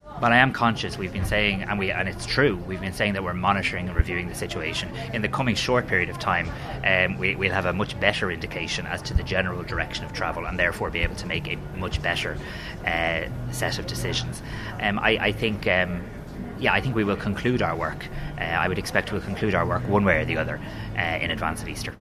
Speaking in London, Tánaiste and Finance Minister Simon Harris says the government will make a move one way or another, before the Dáil breaks for Easter: